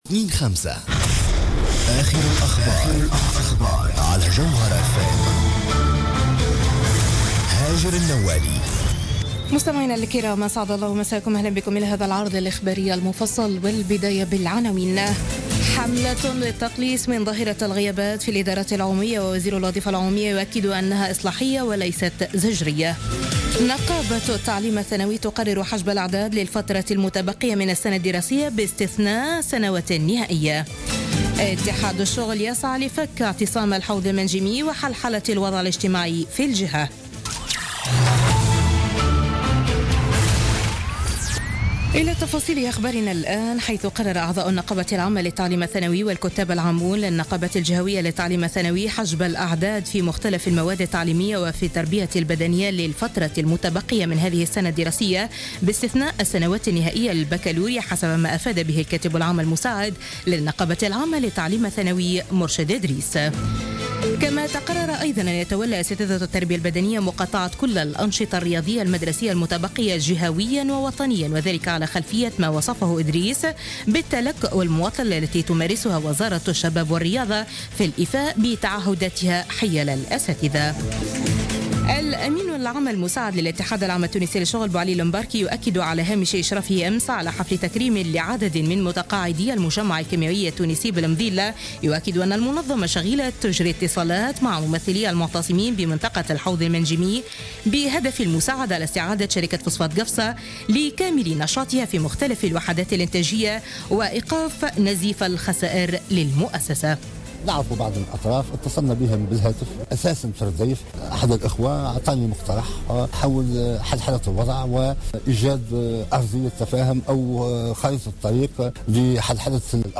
Journal Info 00h00 du lundi 09 mai 2016